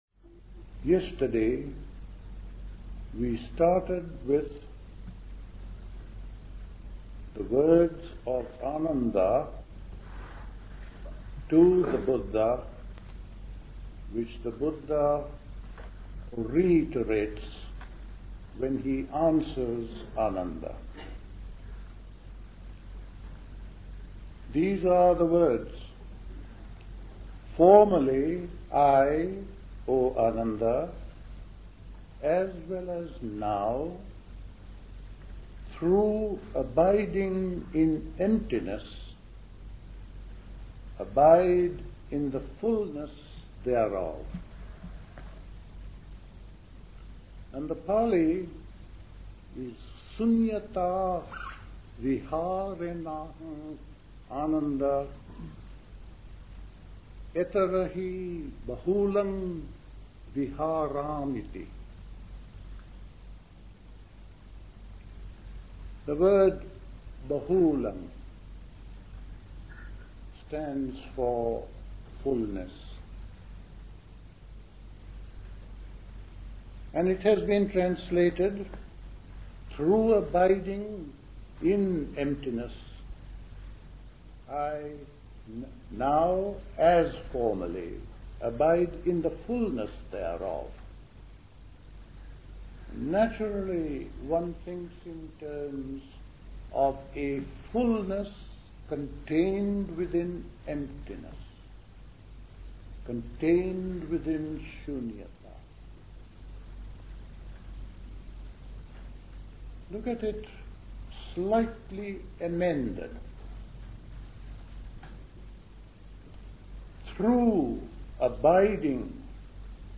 Recorded at the 1975 Buddhist Summer School.